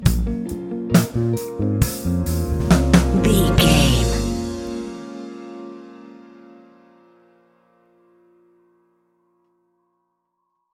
Epic / Action
Fast paced
In-crescendo
Uplifting
Ionian/Major
A♯
hip hop